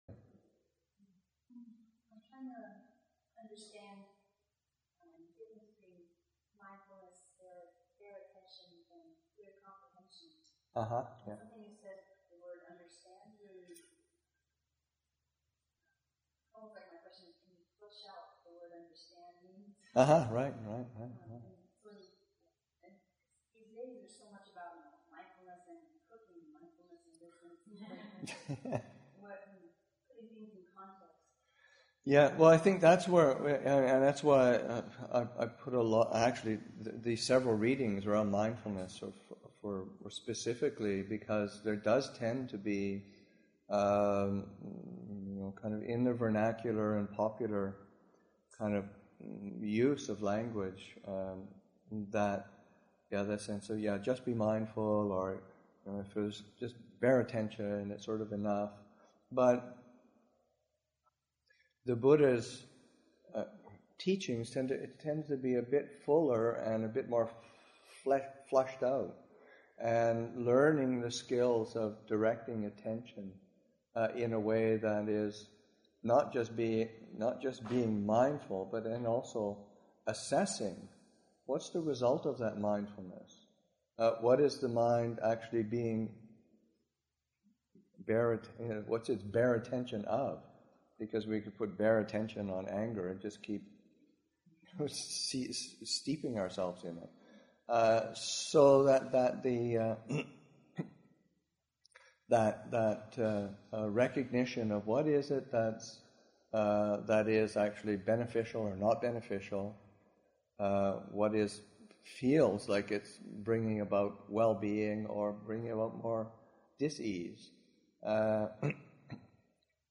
Brightening the Mind, Session 1 – Aug. 19, 2012